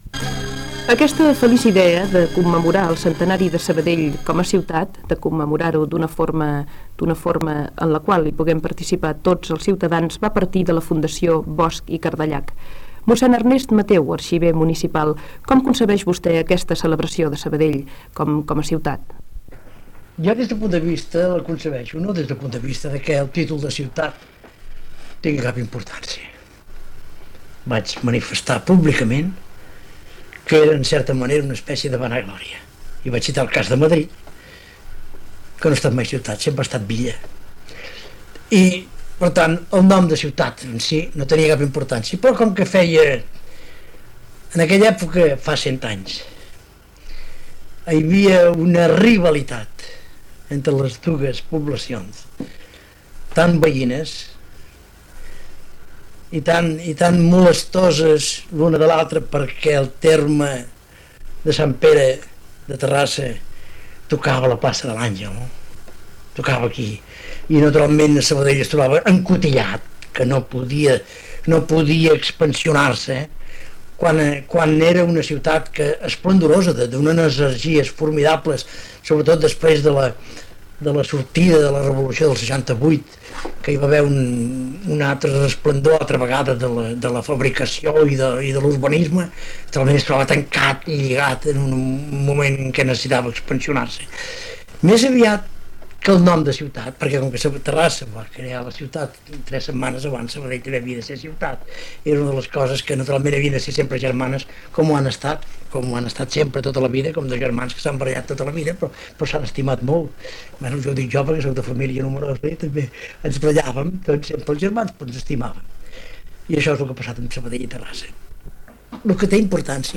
Gènere radiofònic Entreteniment